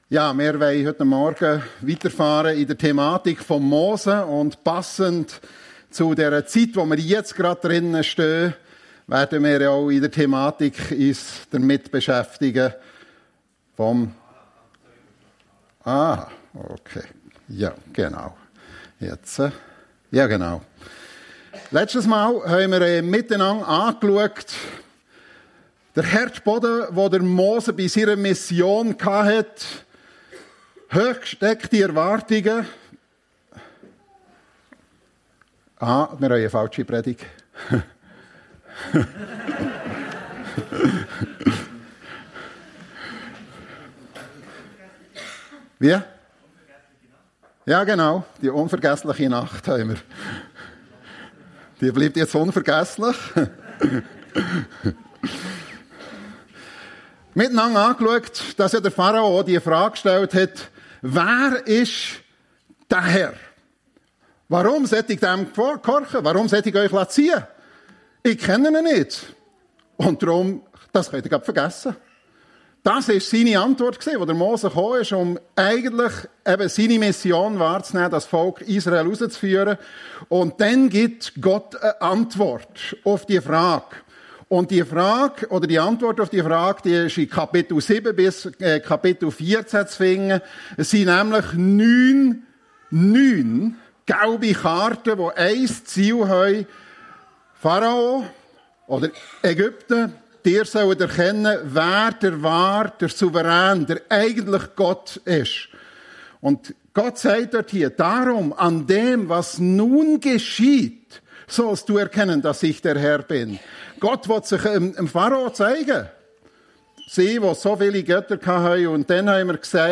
Mose - Unvergessliche Nacht ~ FEG Sumiswald - Predigten Podcast